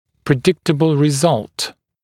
[prɪ’dɪktəbl rɪ’zʌlt][при’диктэбл ри’залт]предсказуемый результат